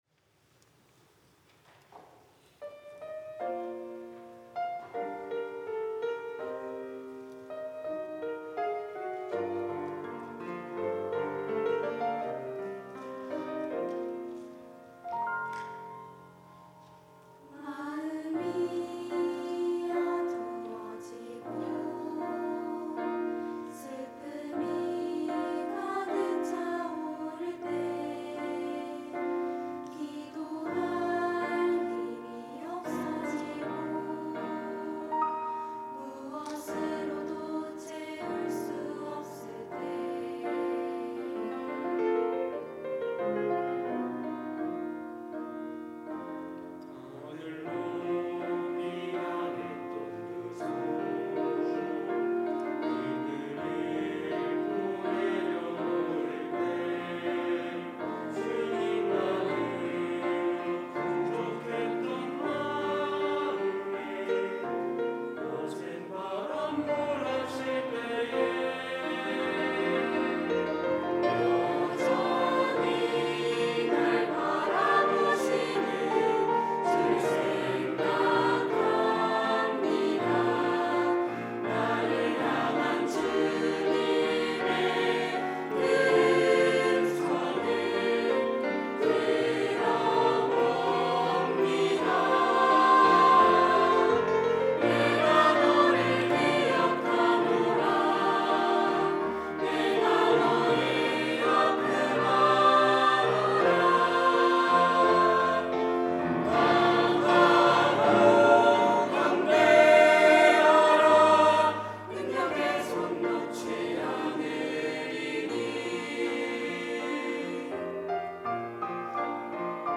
특송과 특주 - 강하고 담대하라
청년부 카이노스 찬양대